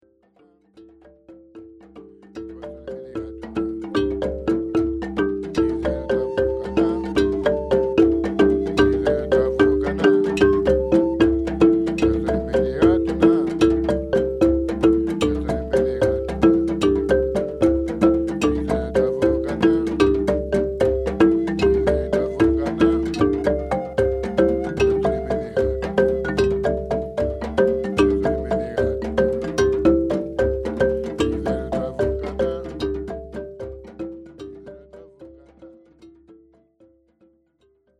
African-inspired